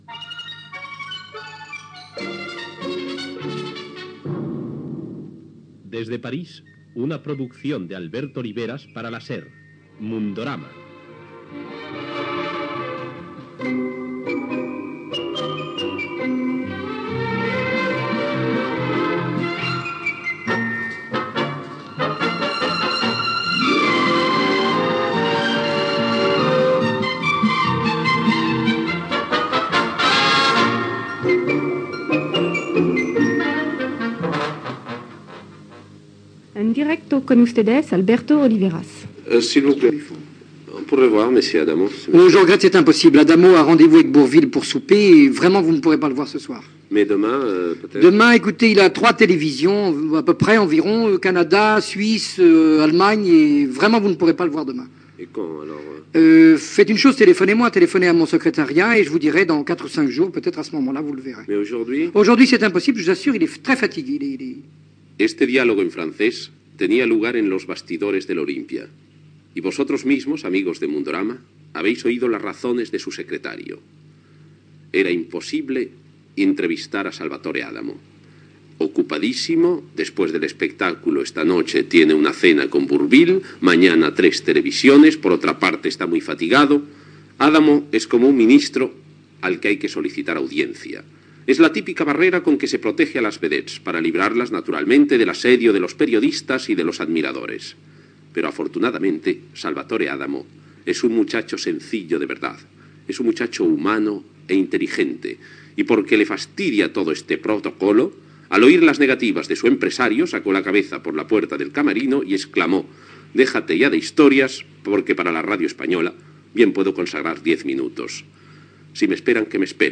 Careta, programa dedicat a l'actuació de Salvatore Adamo al teatre Olympia de París i una entrevista al cantant.